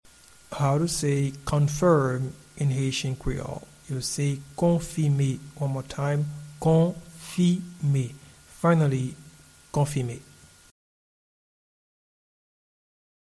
Pronunciation and Transcript:
Confirm-in-Haitian-Creole-–-Konfime-1.mp3